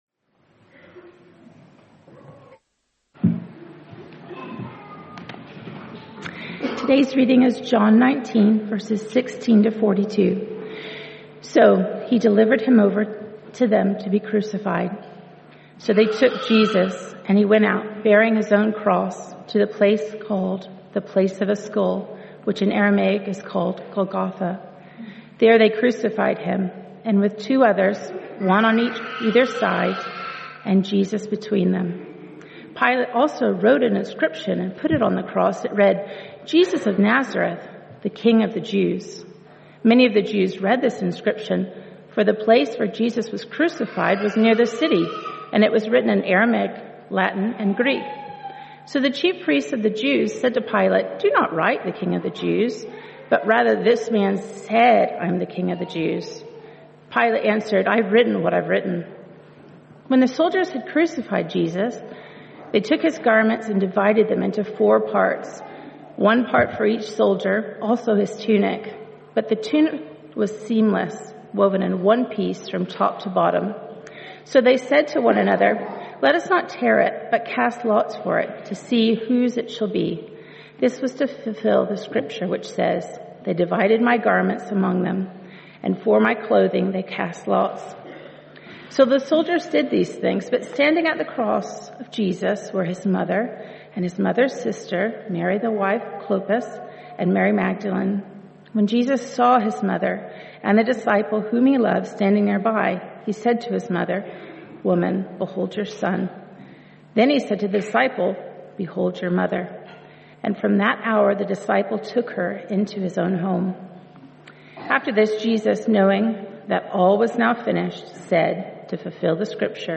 Sermons from Sunday services at Hebron Evangelical Church, Aberdeen
Morning Sermon from 29 March